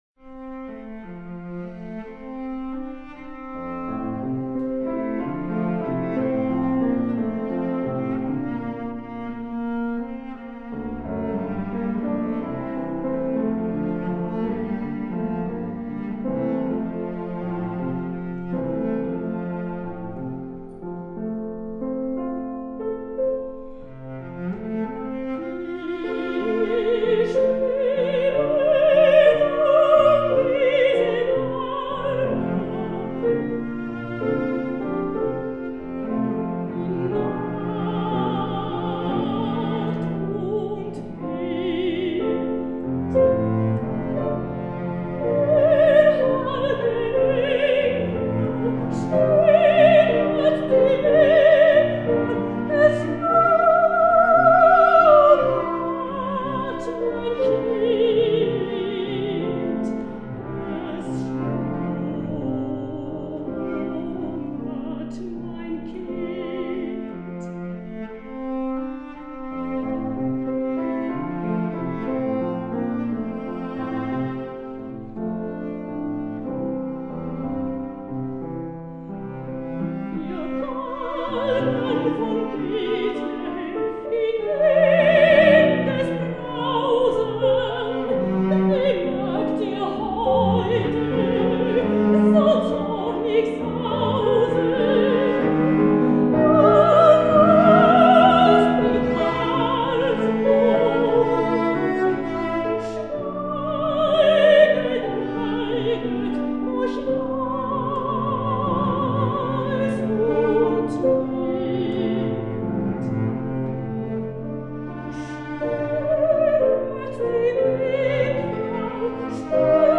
Mezzo Sopran
cello.
live recording Live recital recordings
piano